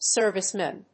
/ˈsɝvʌsmɛn(米国英語), ˈsɜ:vʌsmen(英国英語)/